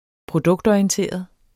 Udtale [ -ɒiənˌteˀʌð ]